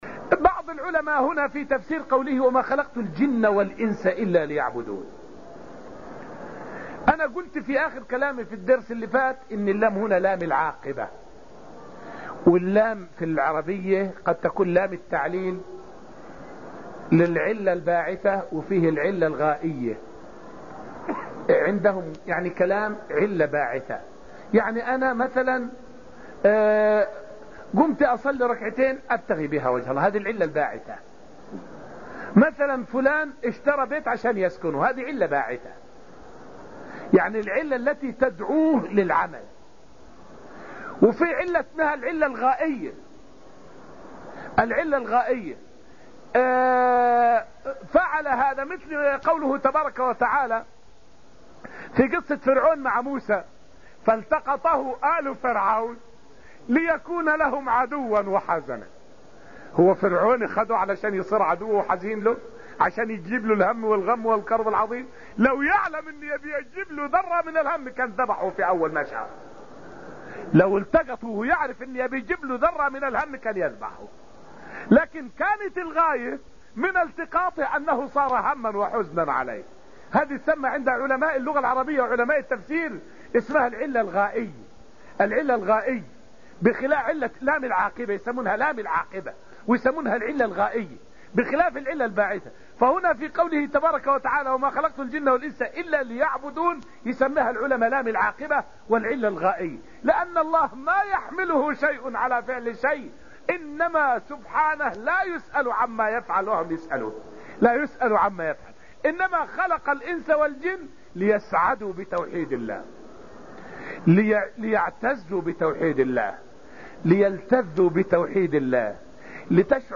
فائدة من الدرس التاسع من دروس تفسير سورة الذاريات والتي ألقيت في المسجد النبوي الشريف حول دلالة اللام في قوله: "ليعبدون".